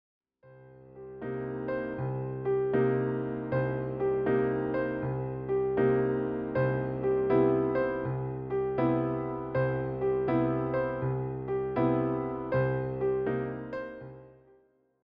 piano pieces